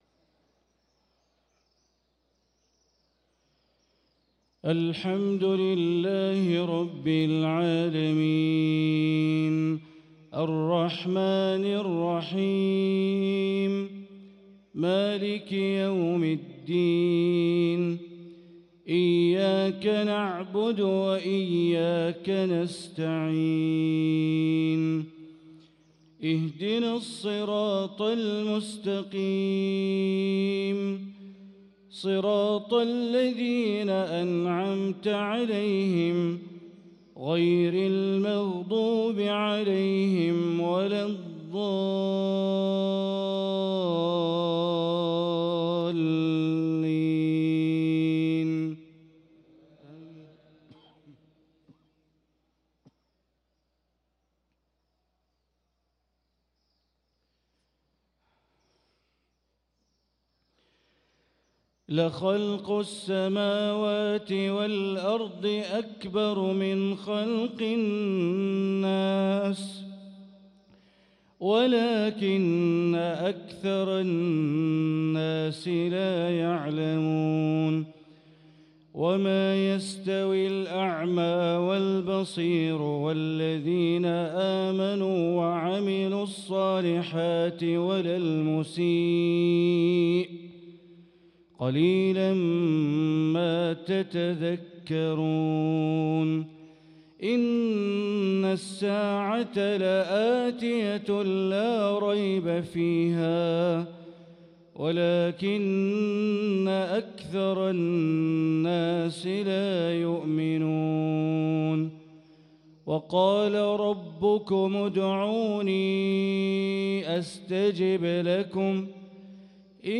صلاة العشاء للقارئ بندر بليلة 26 رجب 1445 هـ
تِلَاوَات الْحَرَمَيْن .